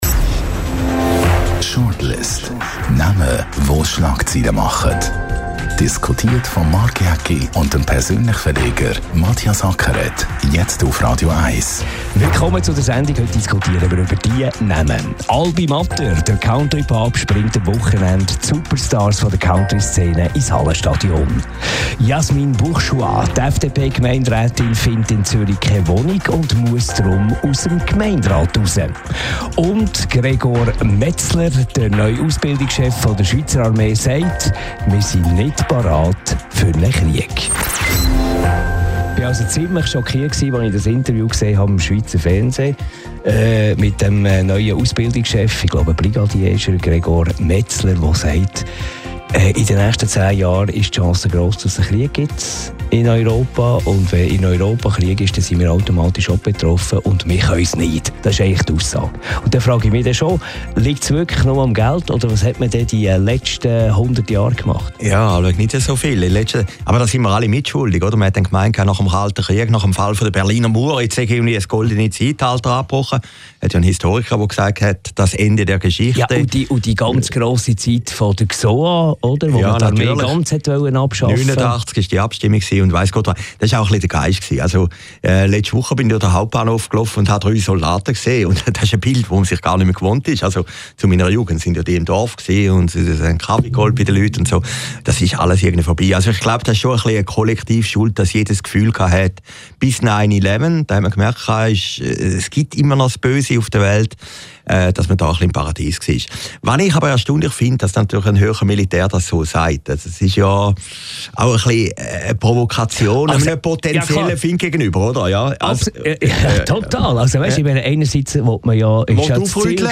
Die Talkshow
Jeden Donnerstag nach 18 Uhr diskutieren die beiden Journalisten über Persönlichkeiten, die für Schlagzeilen sorgen.